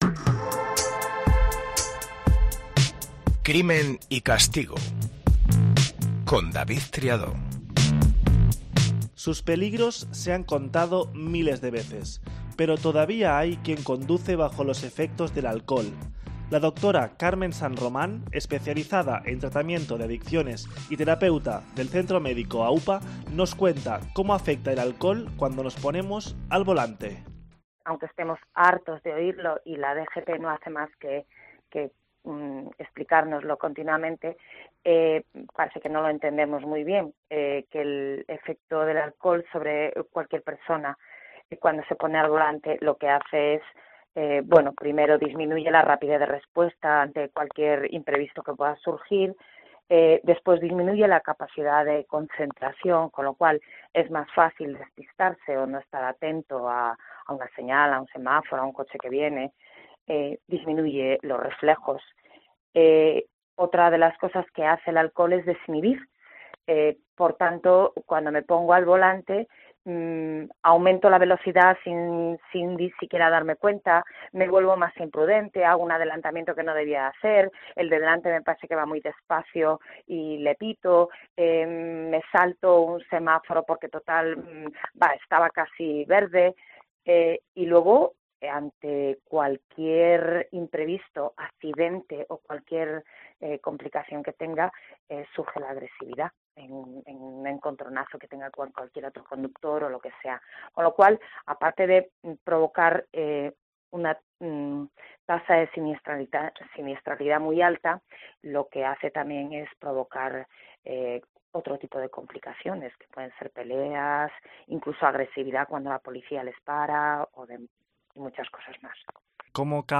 Lo hace en un formato dinámico, fresco, cercano, y con entrevistas a los implicados e intervenciones de expertos.